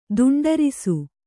♪ duṇḍarisu